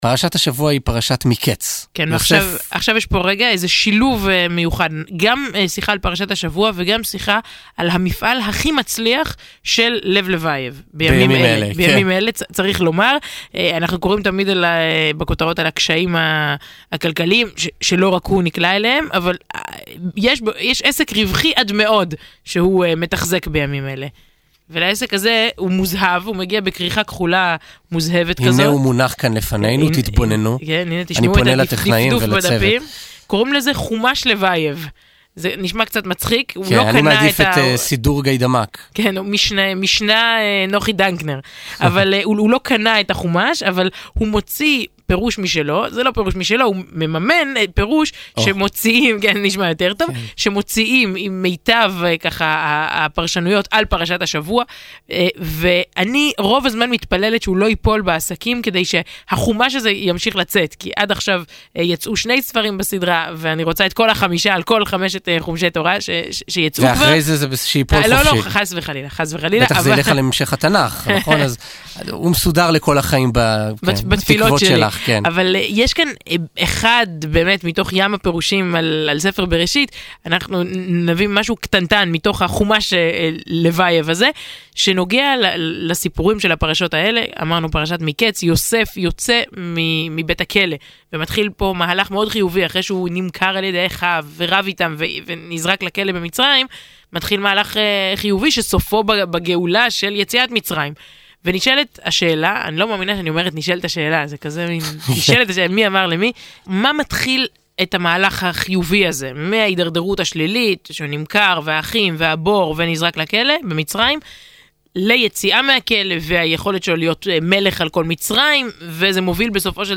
בתכנית האחרונה, בפינה העוסקת בפרשת השבוע, סיפרו השניים למאזינים על "חומש לבייב" ועל רעיון חסידי לפרשת השבוע להאזנה